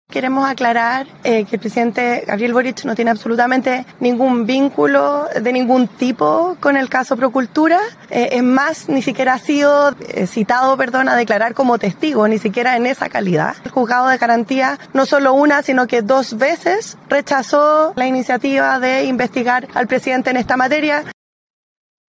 Por ejemplo, la ministra vocera (s), Aysén Etcheverry, sostuvo que el Presidente Boric no figura como imputado, ni como testigo, en la causa, y recordó que la justicia ha rechazado en dos ocasiones la solicitud del fiscal Cooper.